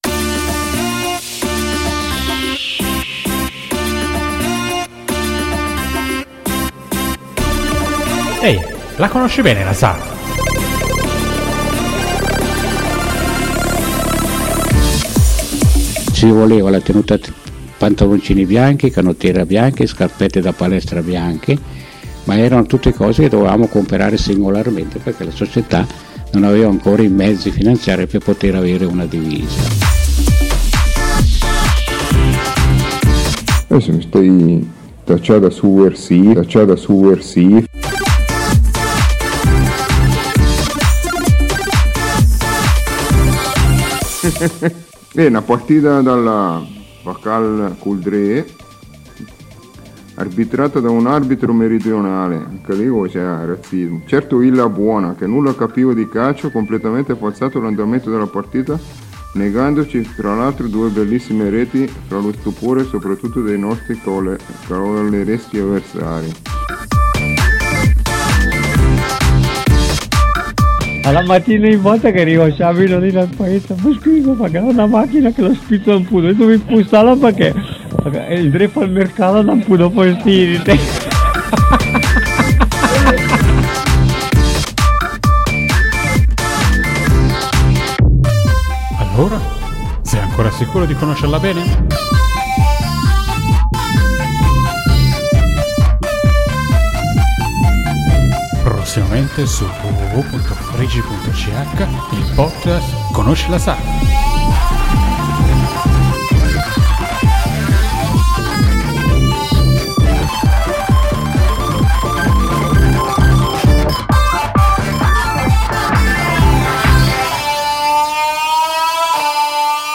Sistemando alcune cose del mio trasloco, sono apparsi dei vecchi CD del 2008 con delle interviste sulla SAV. Sono delle interviste che sono state fatte per il 50mo della SAV, per riscoprire fatti e storie non scritte.